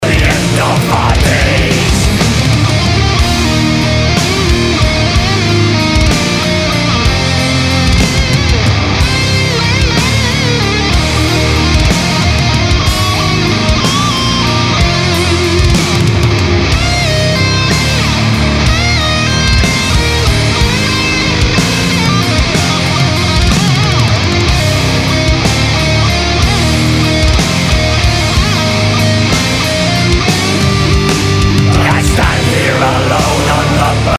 VERY nice, liking that a lot! shits all over my 2 drunk solos.